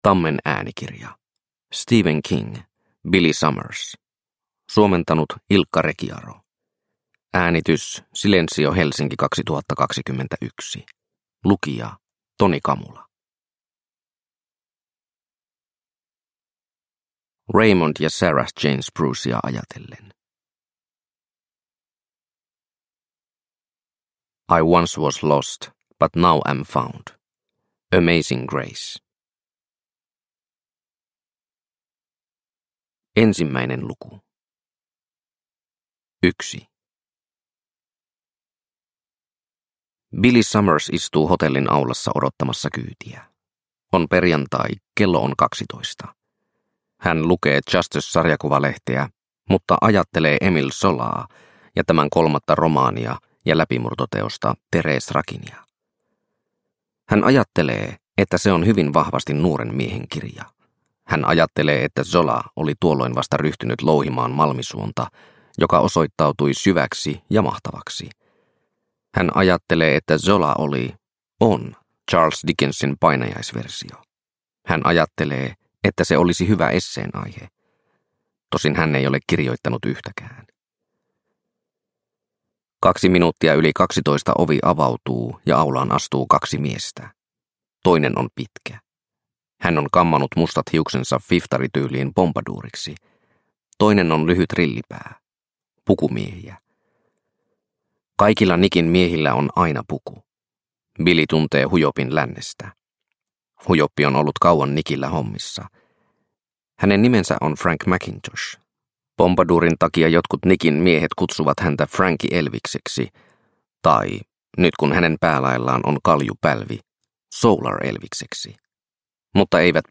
Billy Summers – Ljudbok – Laddas ner